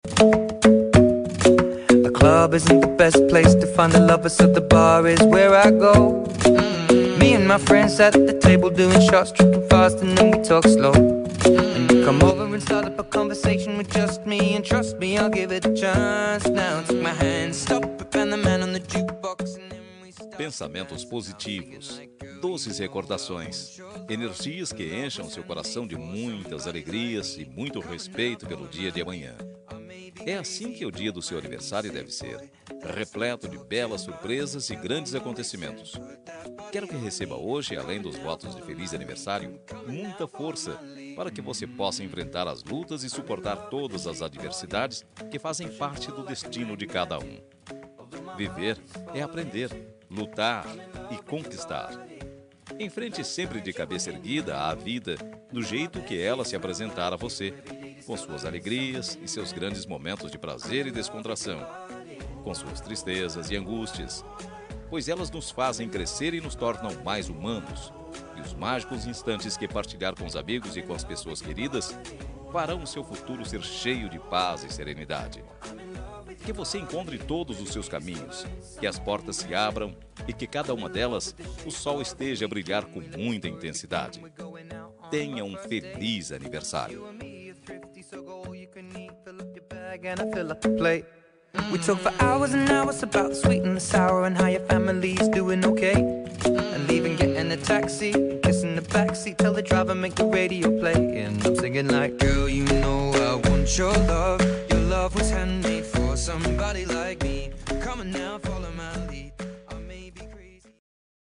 Aniversário de Ficante – Voz Masculina – Cód: 8878
aniv-ficante-mas-8878.m4a